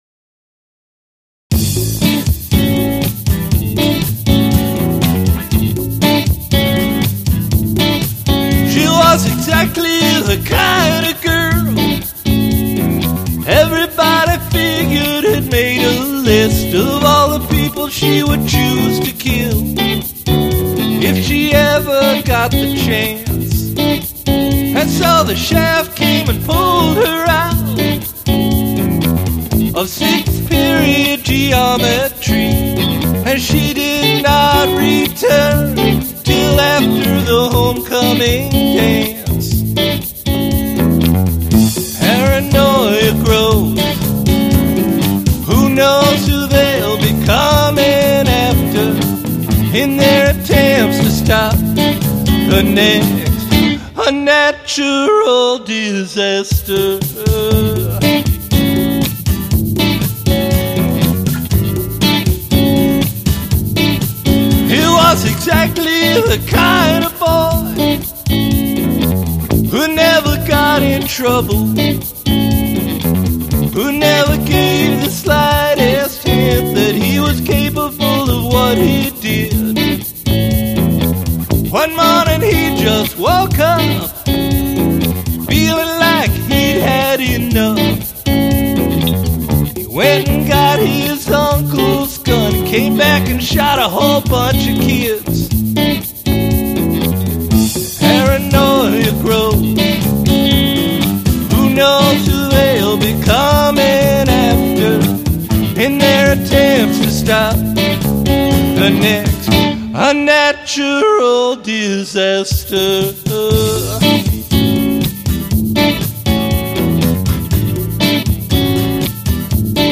Must include prominent use of backwards recording